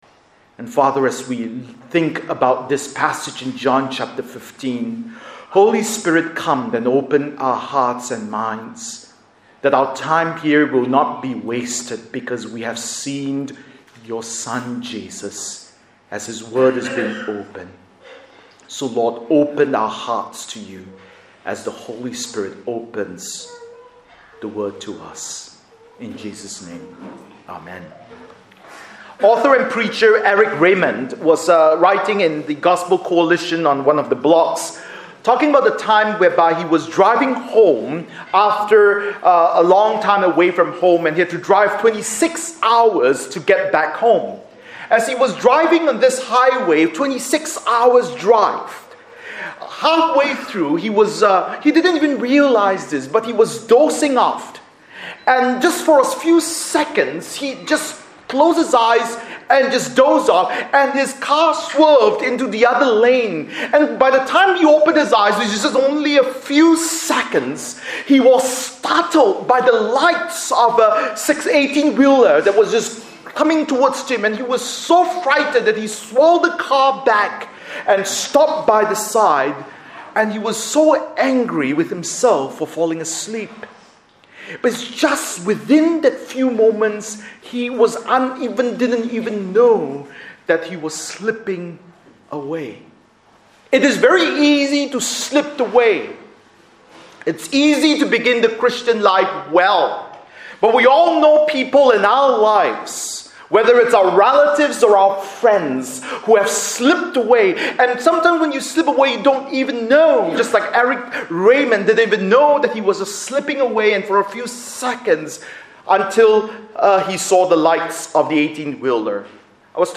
Bible Text: John 15:1-17 | Preacher